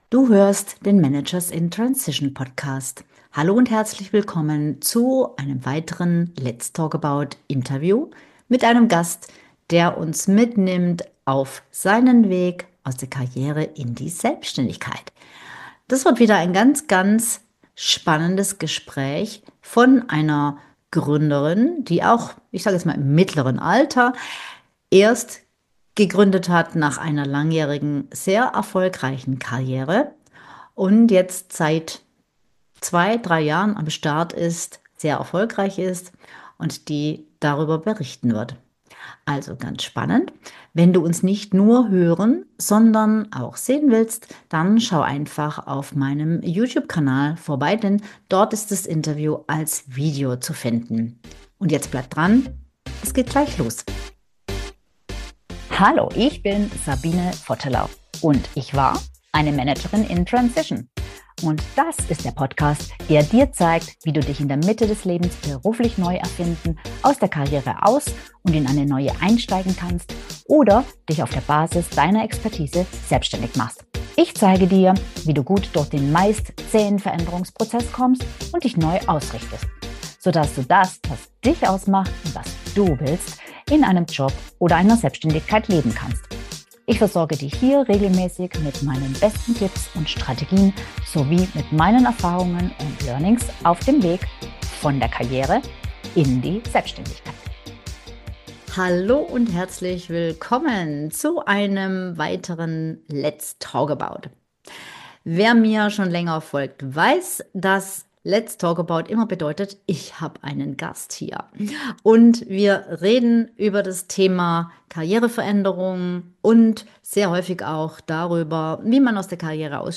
Ein ehrliches Gespräch über mutige Entscheidungen, späte Starts und die Frage: Was wäre, wenn du einfach mal größer denken würdest?